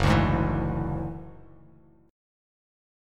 F#7sus2#5 chord